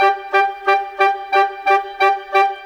Rock-Pop 07 Winds 02.wav